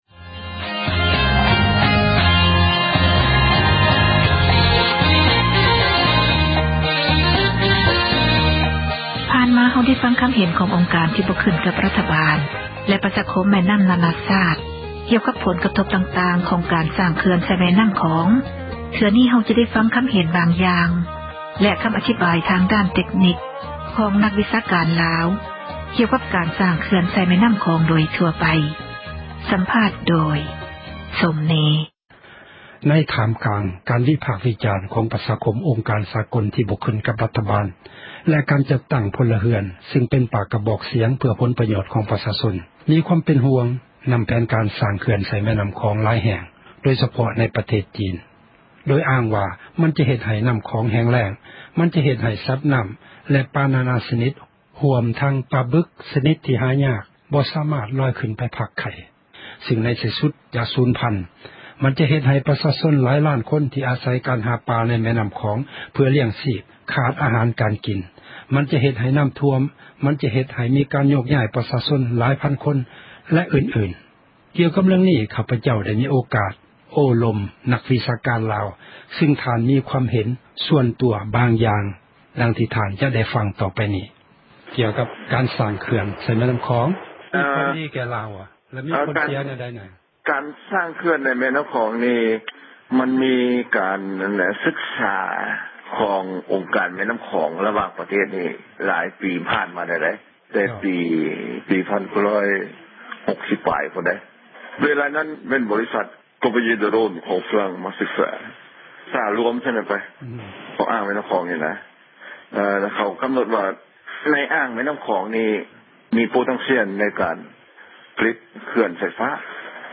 ສໍາພາດນັກວິຊາການ ລາວ ກ່ຽວກັບການສ້າງເຂື່ອນ